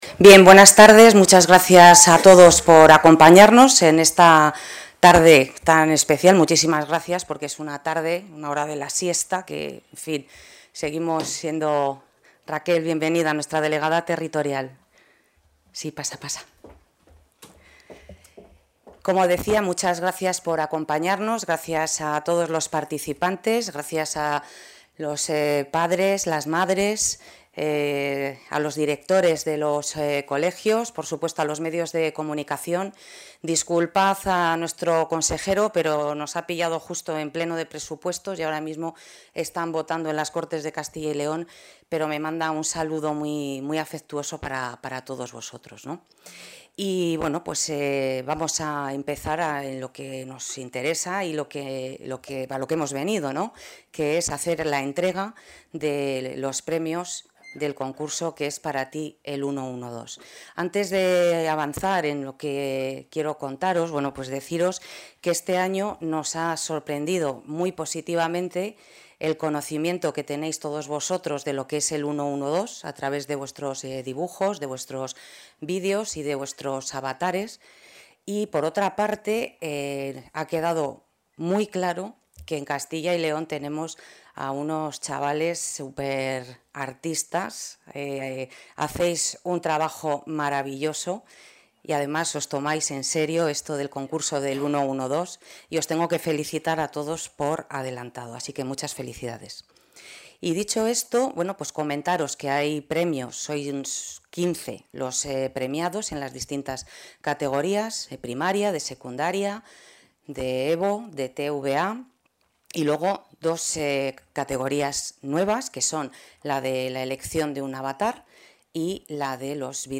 Intervención de la directora.